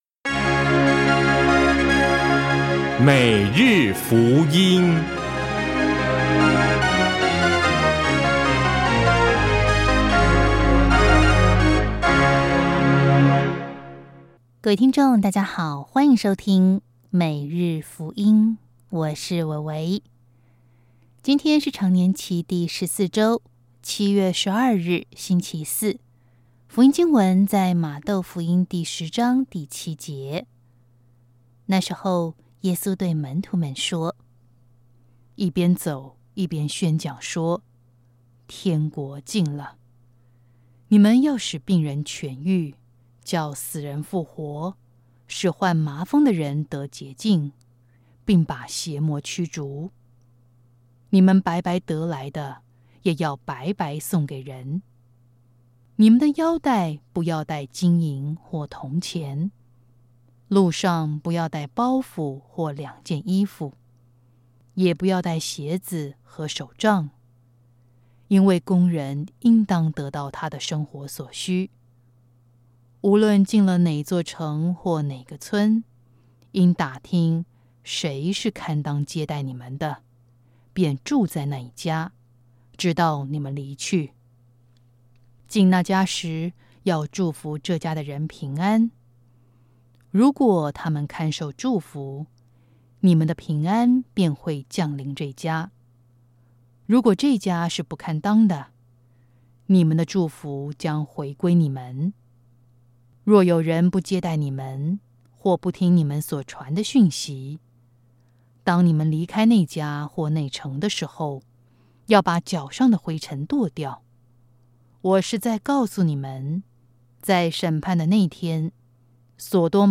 首页 / 证道